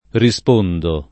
rispondere [riSp1ndere] v.; rispondo [